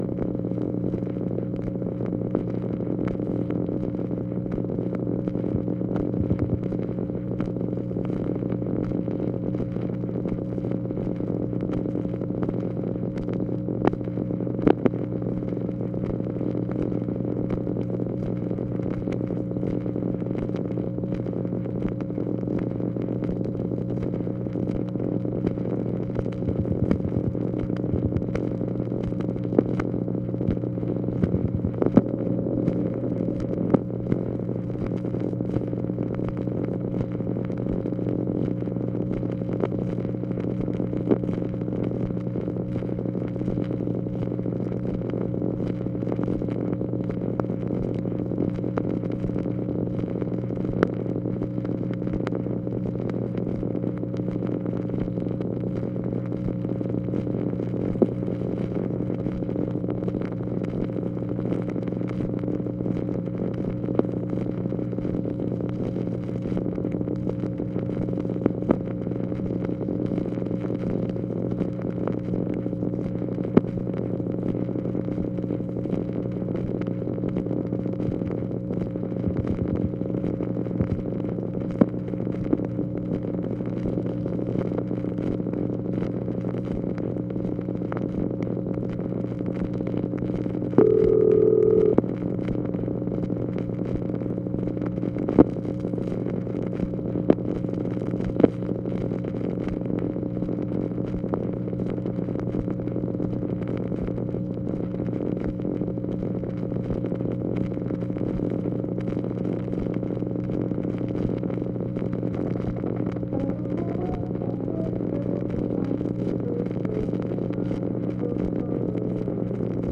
OFFICE NOISE, March 31, 1965
Secret White House Tapes | Lyndon B. Johnson Presidency